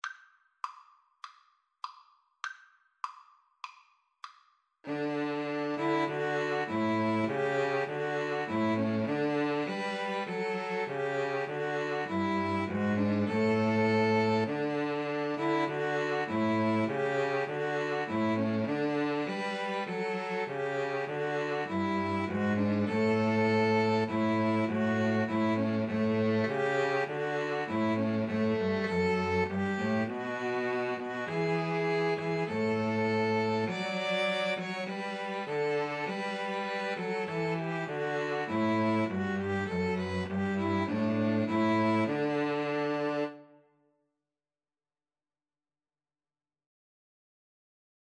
4/4 (View more 4/4 Music)
Classical (View more Classical String trio Music)